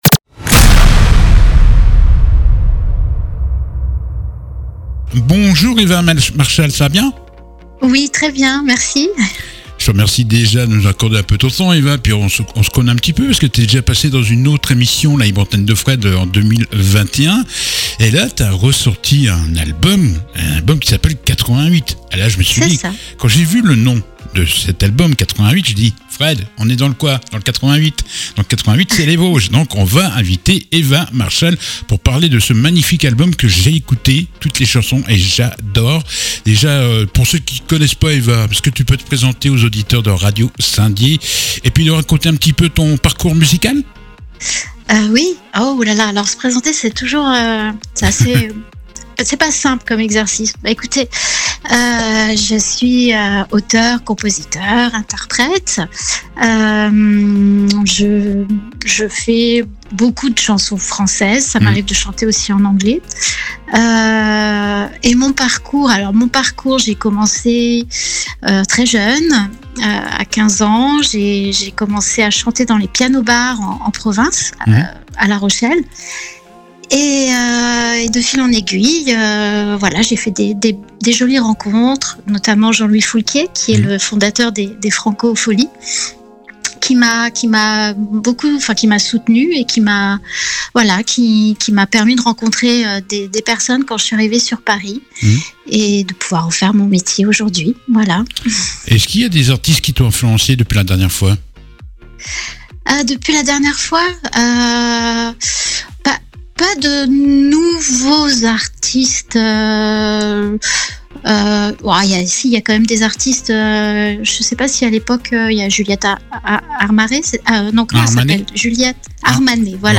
L'interview du jour